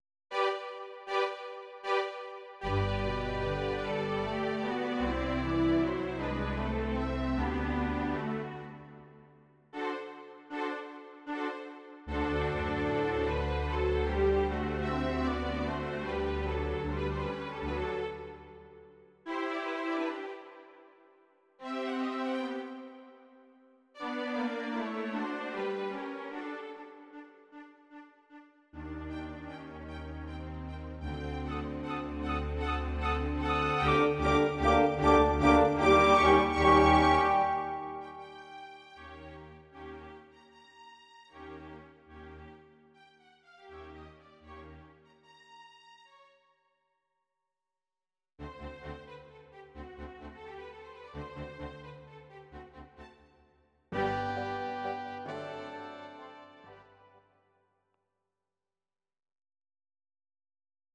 Audio Recordings based on Midi-files
Classical, Instrumental